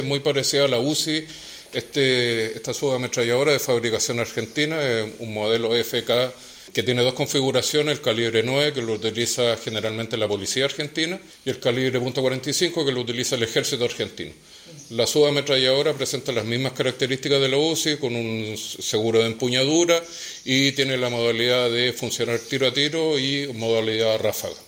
El jefe de zona de Carabineros en La Araucanía, el general Miguel Herrera, explicó que las subametralladoras tienen dos modalidades de uso e indicó que son similares a la UZI que utiliza la policía uniformada.